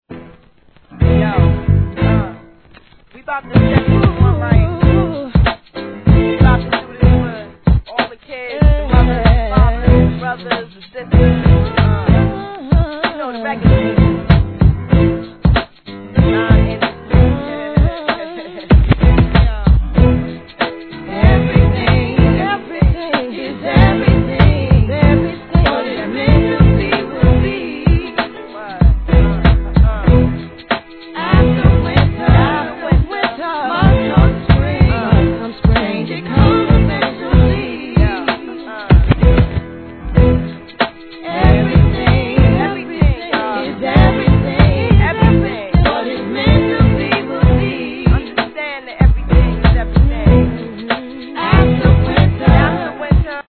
HIP HOP/R&B
ストリングスの印象的なLOOPで歌う力強いフック・コーラスにグッと来ます♪